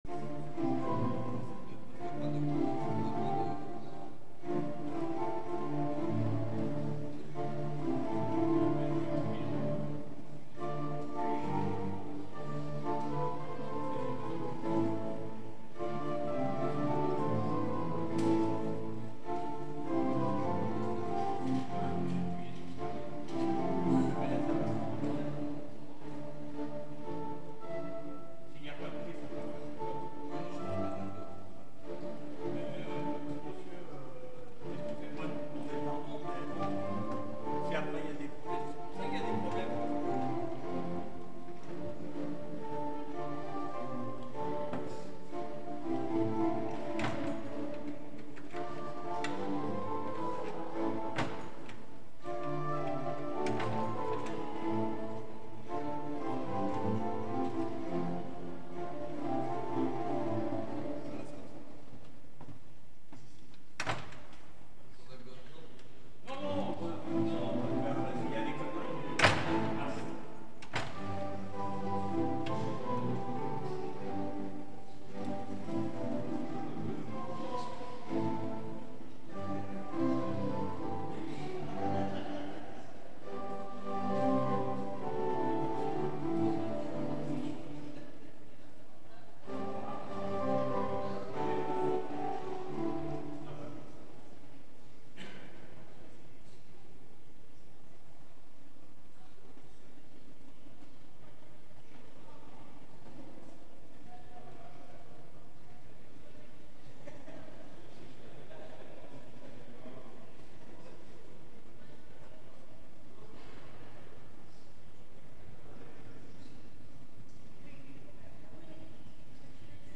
Culte accompagné à l’orgue